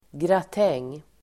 Ladda ner uttalet
gratäng substantiv, gratin Uttal: [grat'eng:] Böjningar: gratängen, gratänger Definition: gratinerad maträtt (dish cooked in a gratin dish) Sammansättningar: fiskgratäng (fish au gratin) gratin substantiv, gratäng Förklaring: gratinerad maträtt